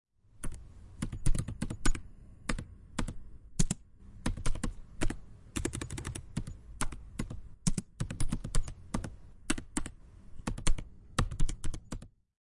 描述：打开计算机服务器/硬盘驱动器的声音。
Tag: 电子 风扇 计算机 机械 嗡嗡声 噪音